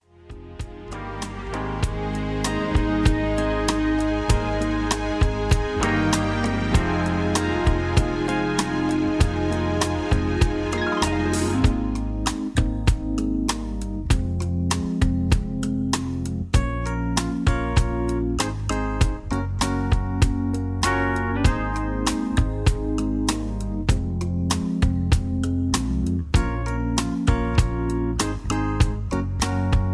Key-Ab) Karaoke MP3 Backing Tracks
Just Plain & Simply "GREAT MUSIC" (No Lyrics).